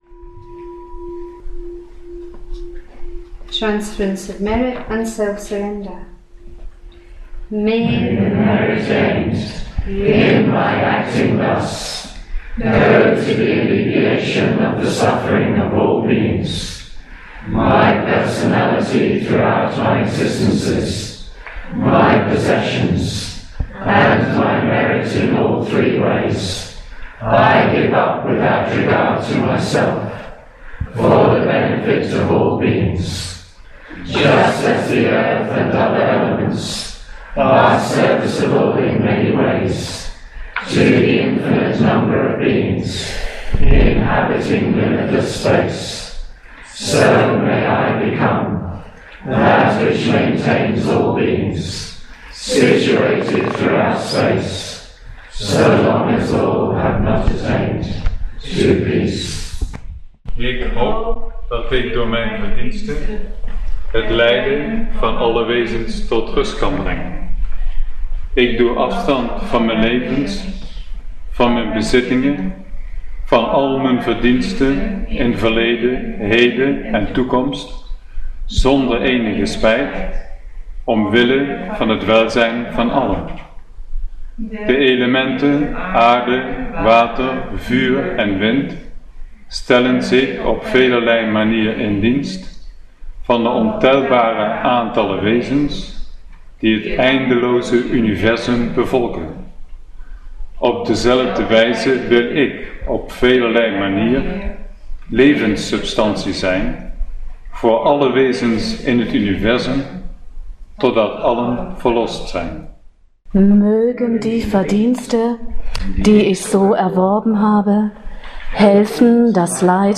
In order the languages are English, Dutch, German, Swedish, Welsh, French, Spanish, Polish, Russian, Estonian, and Danish - each read or recited by the Chair or other representative of that Buddhist Centre, sometimes in a small group. It was recorded on the European Chairs Assembly - the first event held at Triratna’s new retreat centre Adhisthana - in August 2013.